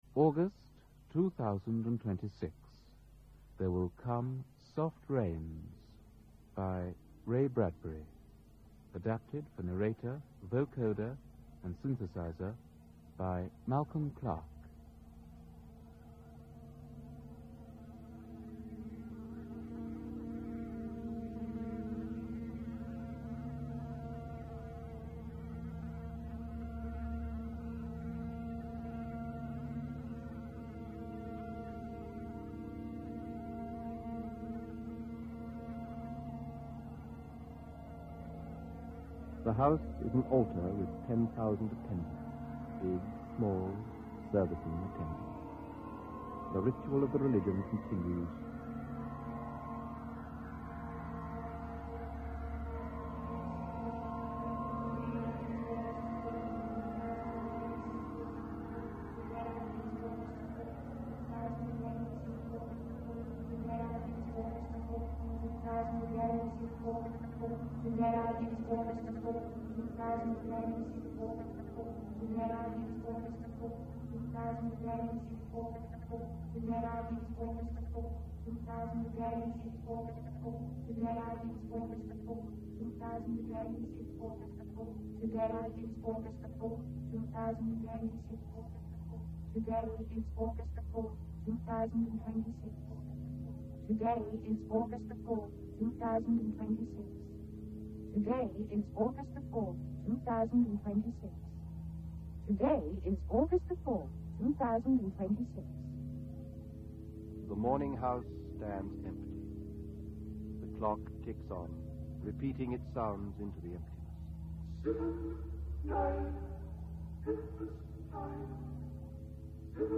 The production makes extensive use of the then new Vocoder, an electronic device for manipulating the human voice, and musicalising speech. The one unmodified voice in the production is that of the narrator